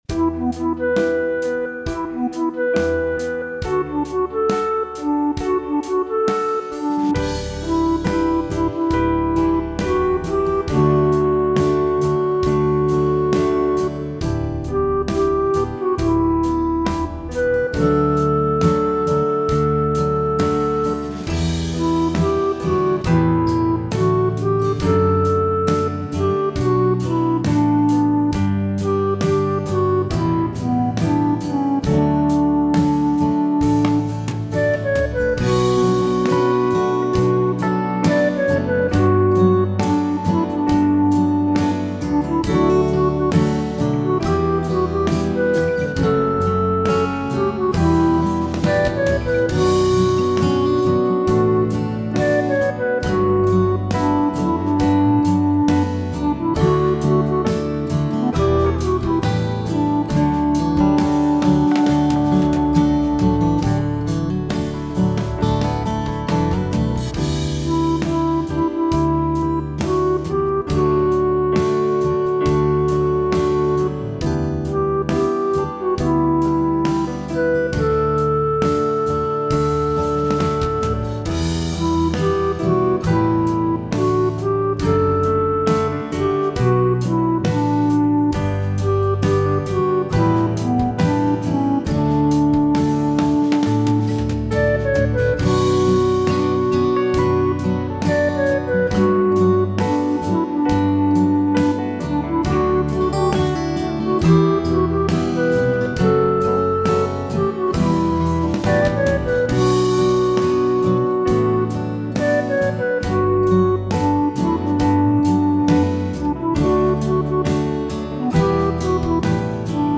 it just screamed funk to me.